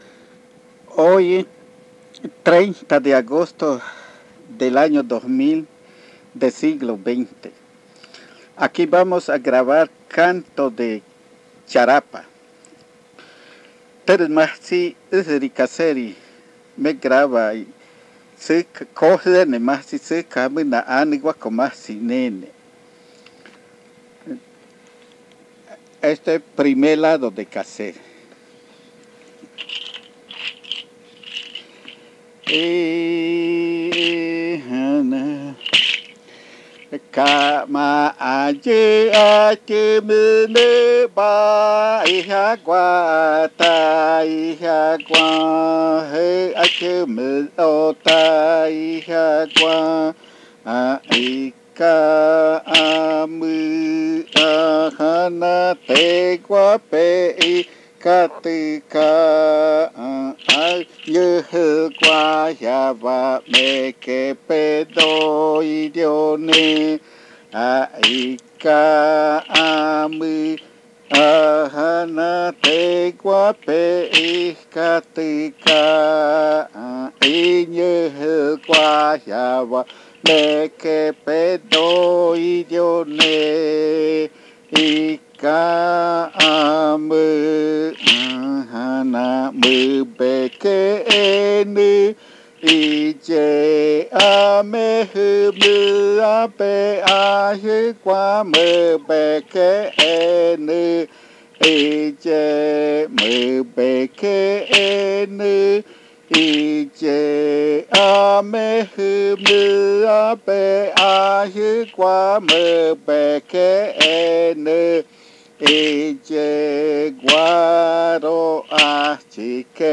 Lado A: Aanʉgwako majtsi (Cantos de semillas de yuca).
entre los años 1998 y 2000 en Puerto Remanso del Tigre (Amazonas, Colombia).
El audio contiene los lados A y B del casete #4.